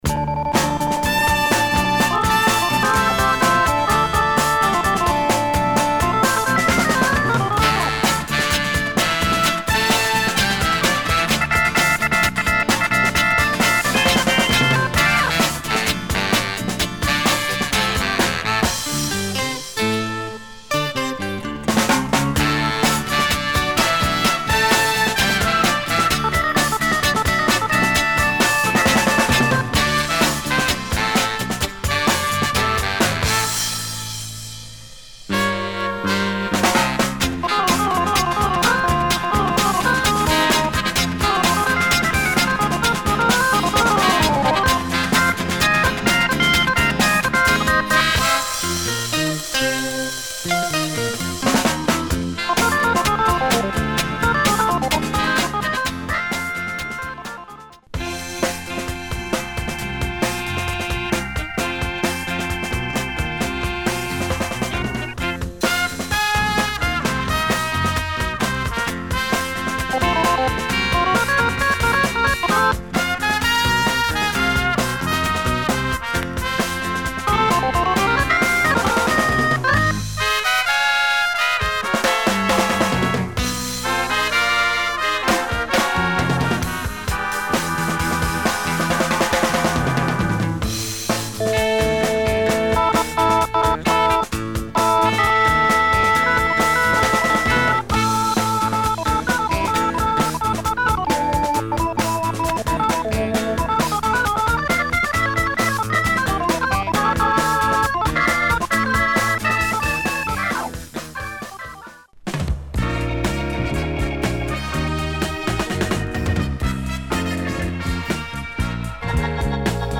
Very cool price for this very solid British library LP.
Organ storm !